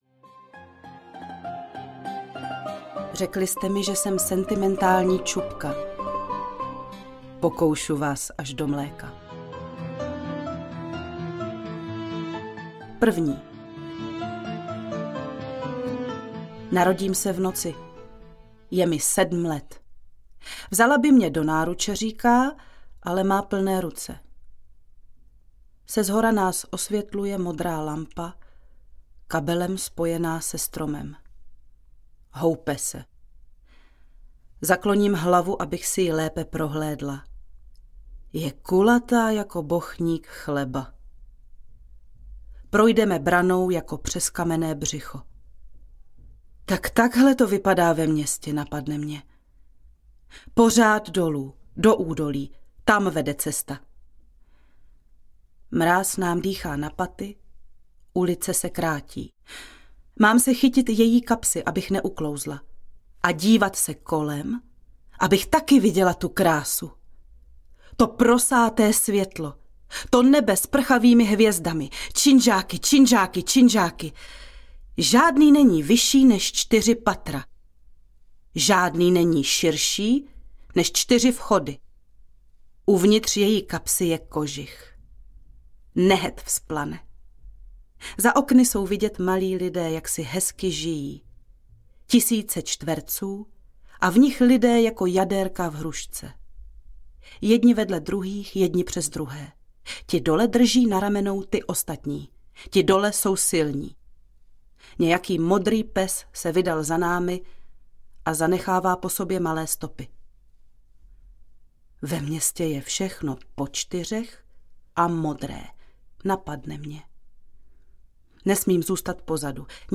Skleněná zahrada audiokniha
Ukázka z knihy